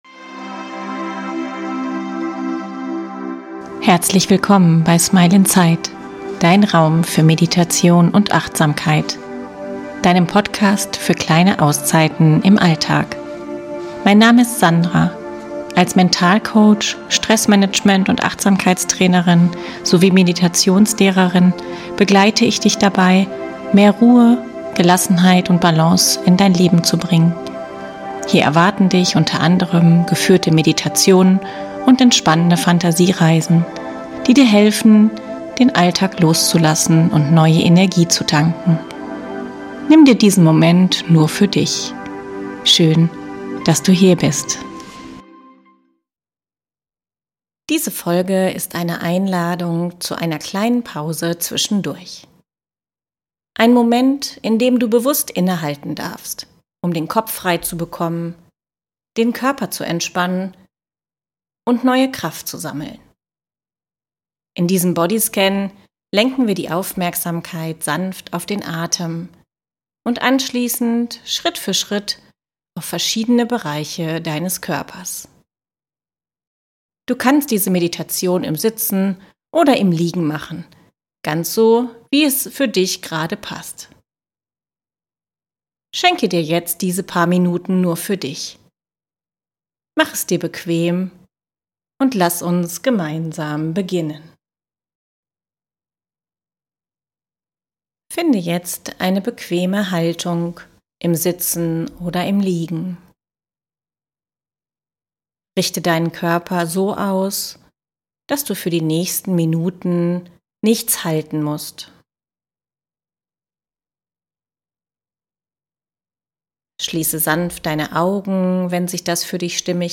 Dieser kurze Bodyscan ist eine Einladung, zwischendurch bewusst innezuhalten, den Körper zu entspannen und den Geist zur Ruhe kommen zu lassen. In dieser Meditation richtest du deine Aufmerksamkeit zunächst auf den Atem und lässt mit jedem langsamen Ausatmen mehr Anspannung los. Anschließend wanderst du achtsam durch den Körper, nimmst einzelne Bereiche wahr und darfst Schritt für Schritt herunterfahren.